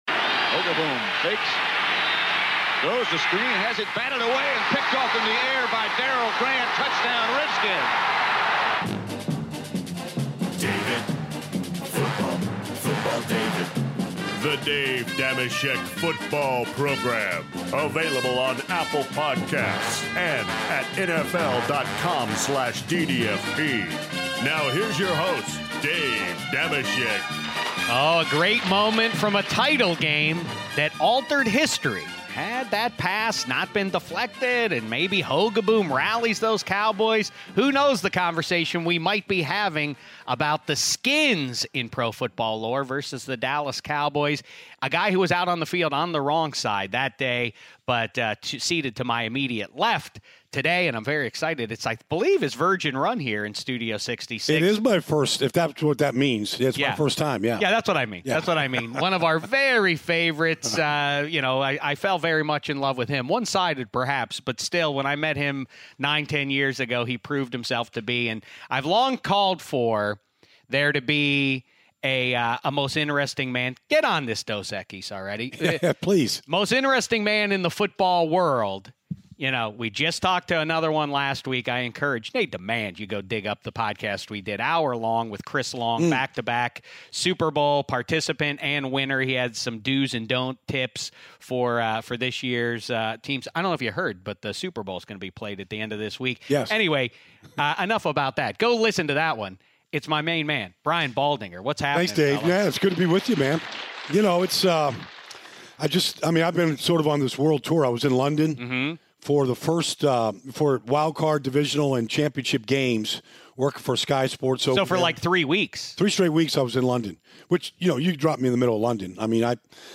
Dave Dameshek is joined in Studio 66 by NFL Network's own Brian Baldinger! Baldy kibitzes with Shek about the Mahomes & Marino comparison (12:18) , the future of Brady, Brees & Rivers (8:42), why the Super Bowl trends towards defense (19:53) and they remember the life of Kobe Bryant (3:10).